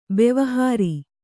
♪ bevahāri